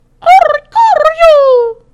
infinitefusion-e18/Audio/SE/Cries/ORICORIO_2.mp3 at releases-April